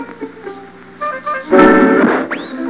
smash.wav